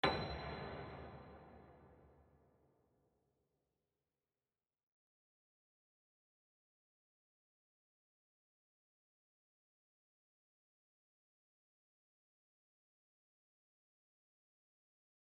piano-sounds-dev
b6.mp3